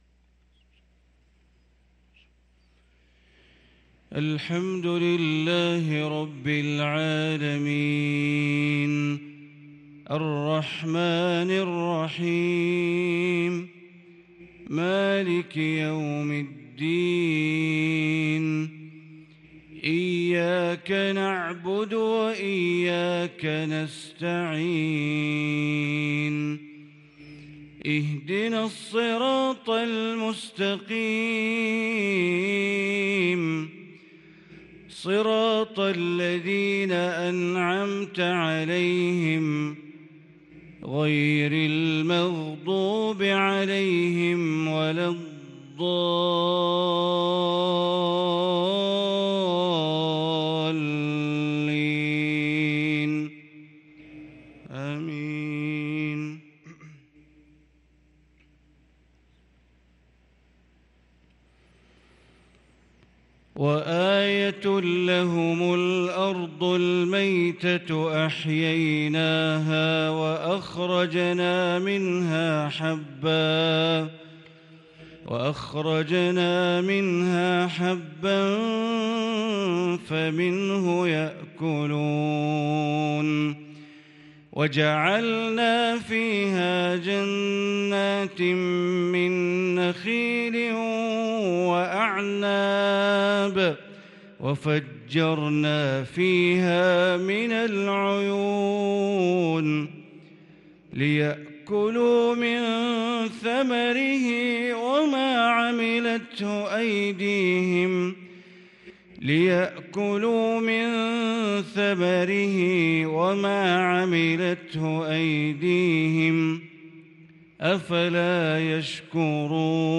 صلاة الفجر للقارئ بندر بليلة 9 ربيع الأول 1444 هـ
تِلَاوَات الْحَرَمَيْن .